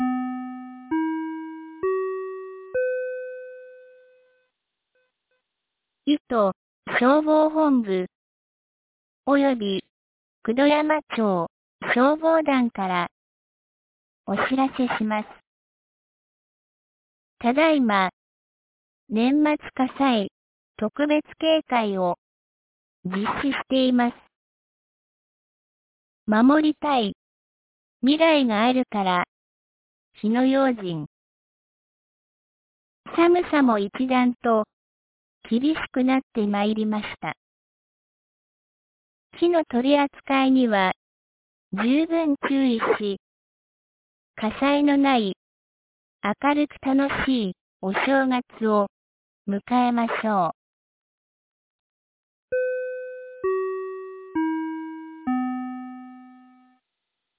2024年12月26日 12時11分に、九度山町より全地区へ放送がありました。